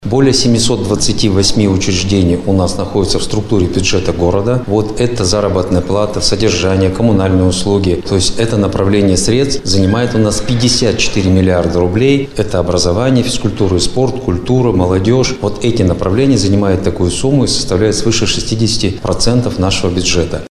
О городском бюджете рассказал на брифинге замглавы города Андрей Корюков.